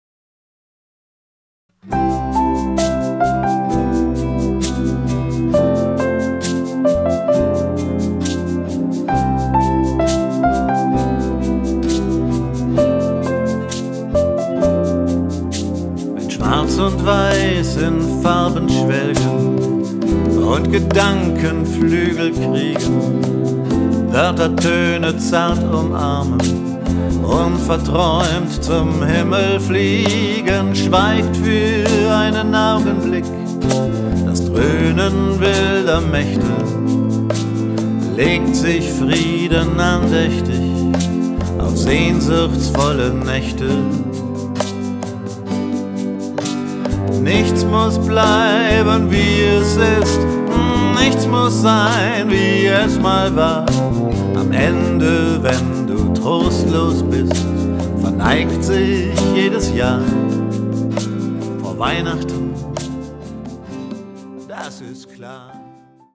Piano, Keyboards
Percussion
Vocals, Gitarren